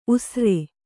♪ usre